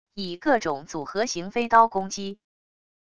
以各种组合型飞刀攻击wav音频